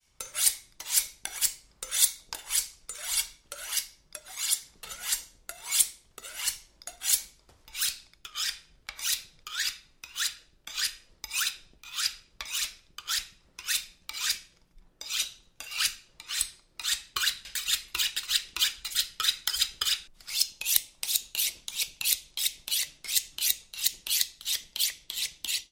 Слушайте онлайн или скачивайте бесплатно резкие, металлические скрежеты и ритмичные движения точильного камня.
Шуршание стали о точильный камень